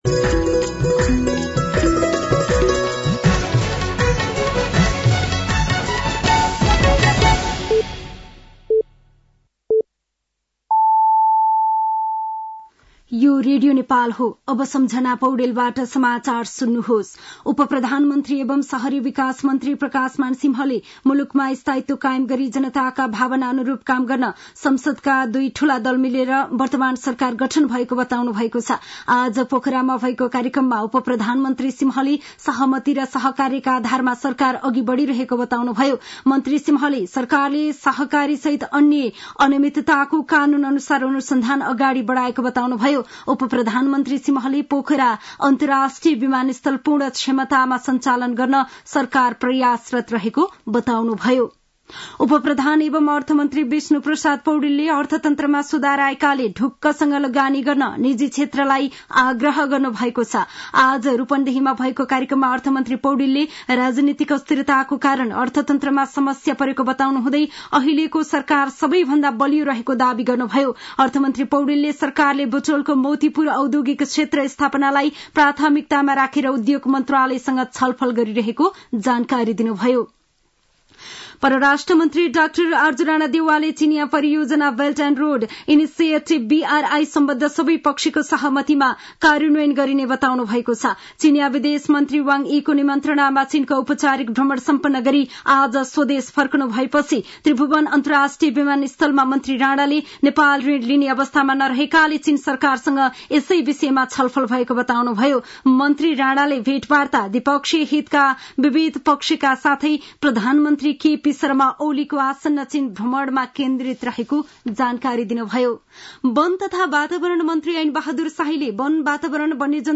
साँझ ५ बजेको नेपाली समाचार : १६ मंसिर , २०८१